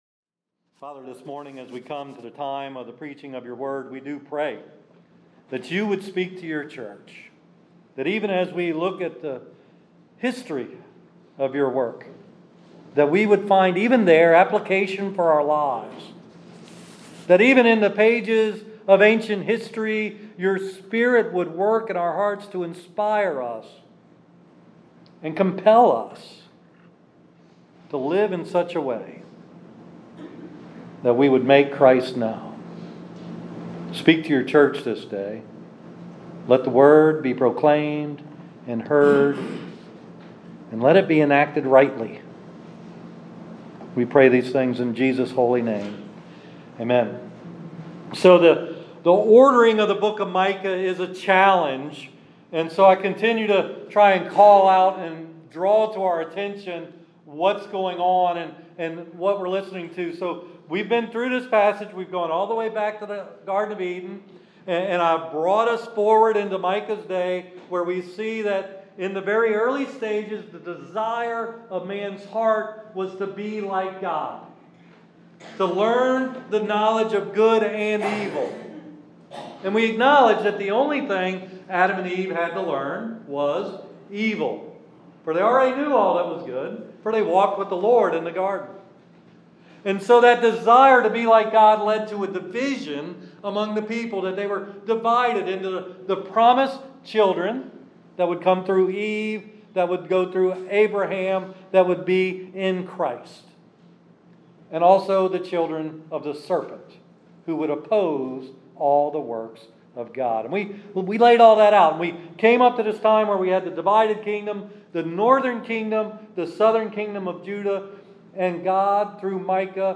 Sorry for the sound issues. My Lapel mic is broken, I can't stand at the pulpit the whole time....